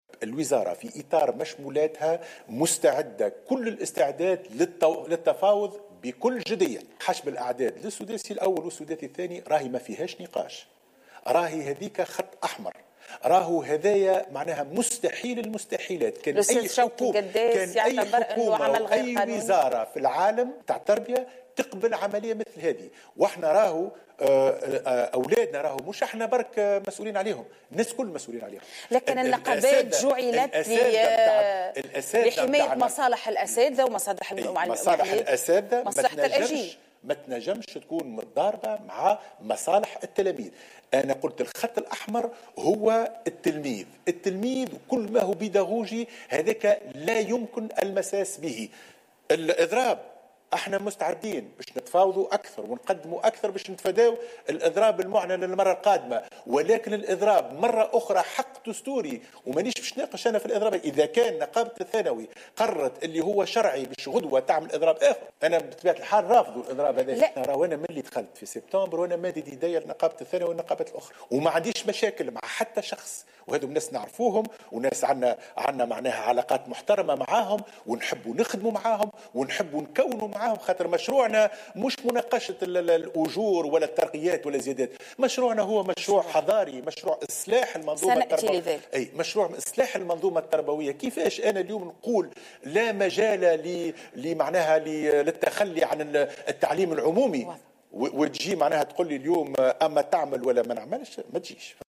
أكد وزير التربية حاتم بن سالم لدى حضوره في برنامج "قهوة عربي" على القناة الوطنية الاولى اليوم الأحد استعداد وزارته للتفاوض بكل جدية مع نقابات التعليم الثانوي تفاديا للإضراب المنتظر في 26 من مارس 2018.